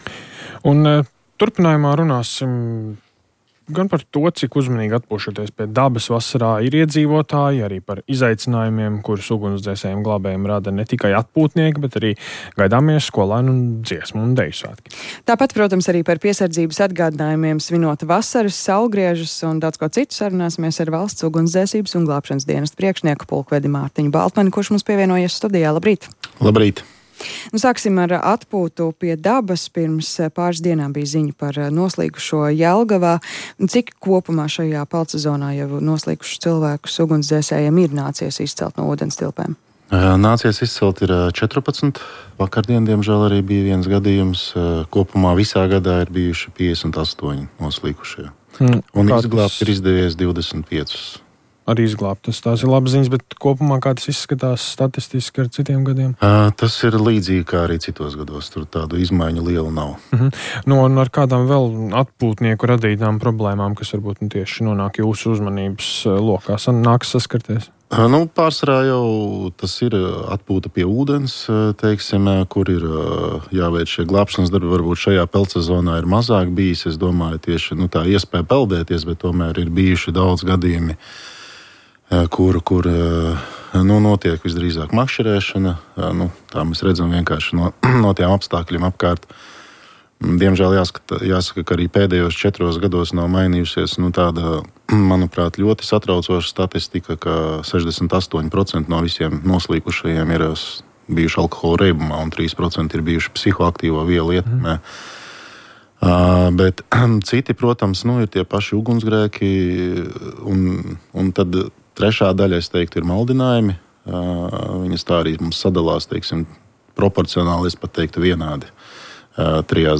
Nākamajā apkures sezonā Rīgā varētu palielināt siltuma tarifu – Rīta intervija – Lyssna här – Podtail